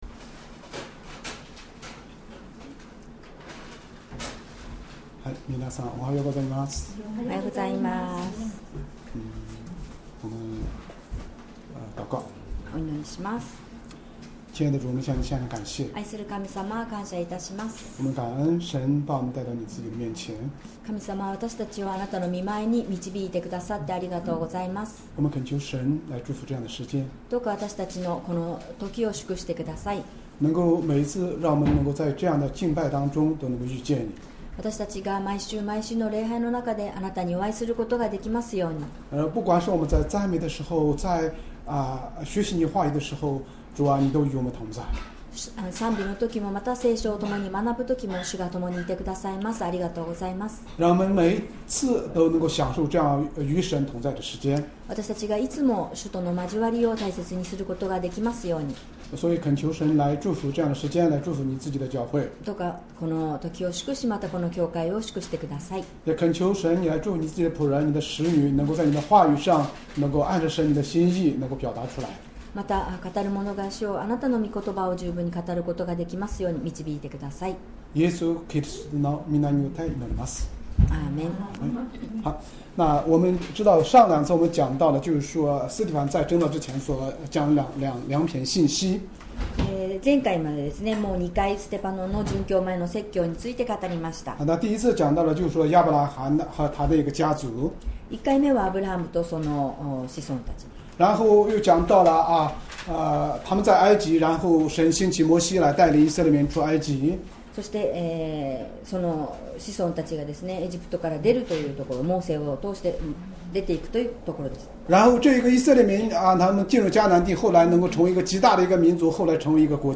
Sermon
2025年1月19日 主日礼拝 説教 「ステパノの殉教前の説教③」 聖書 使徒の働き 7:44-50 7:44 私たちの先祖たちのためには、荒野にあかしの幕屋がありました。